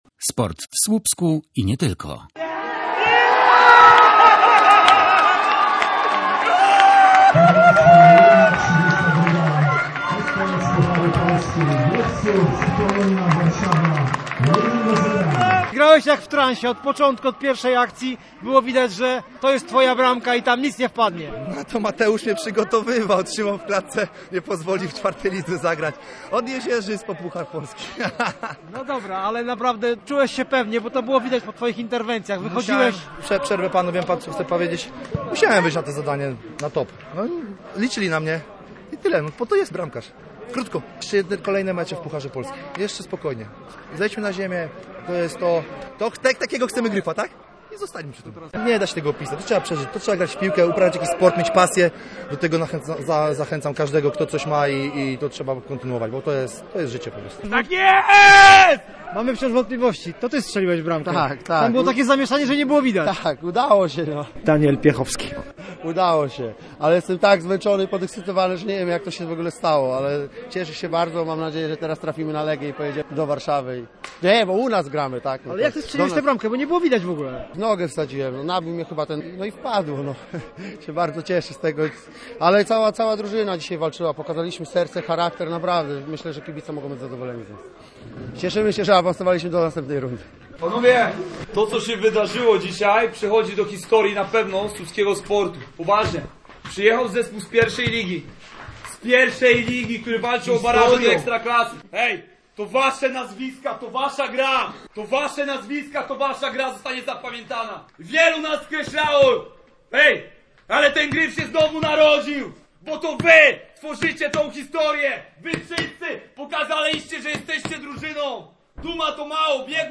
Posłuchaj relacji reportera Radia Gdańsk z meczu w Słupsku: https